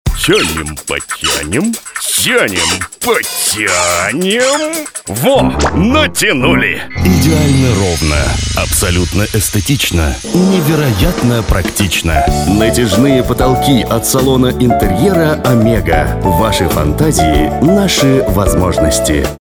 Игровой ролик (2-3 подложки, игровая ситуация, спец эффекты)